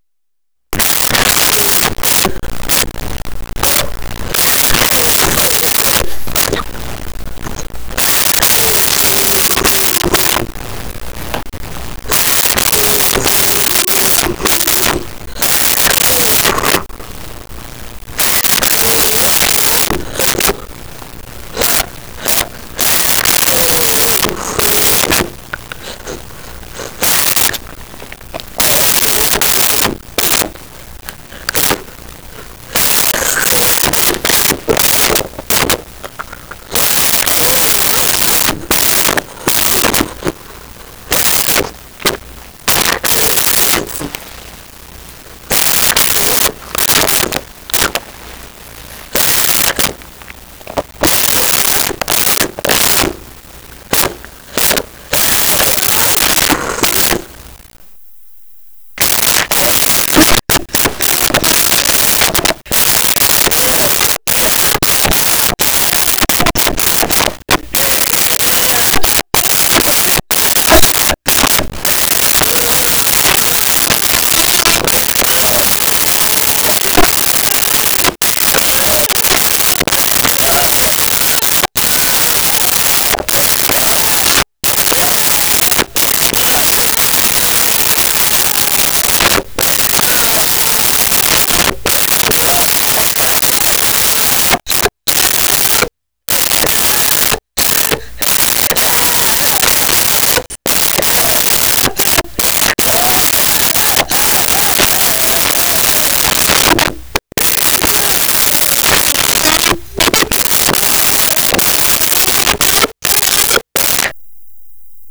Crying Baby Intense Long
Crying Baby Intense Long.wav